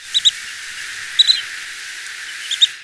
Short-billed Dowitcher
Limnodromus griseus
VOICE: The call note is described as a mellow "tu tu tu".